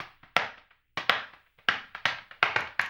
HAMBONE 02.wav